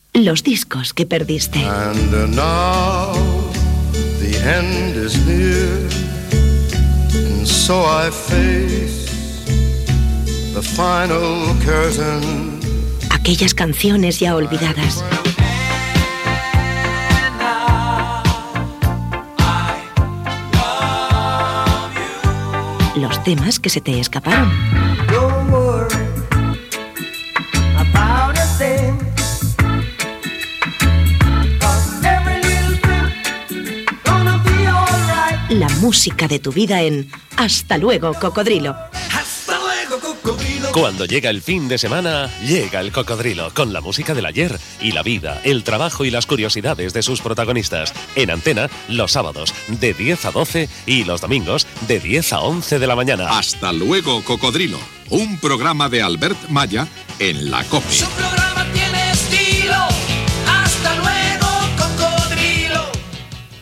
Promoció del programa
Musical
FM